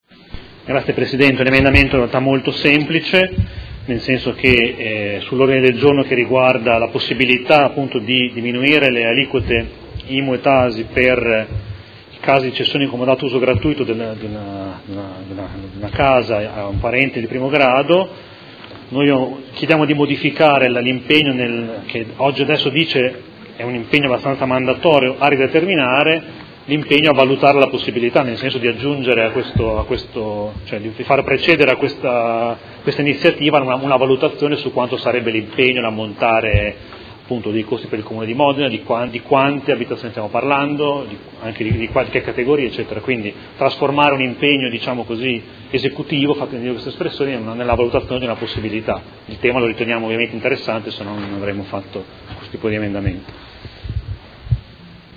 Seduta del 20/12/2018. Presenta emendamento Prot. Gen. 212016 su Ordine del Giorno Prot. Gen. 207452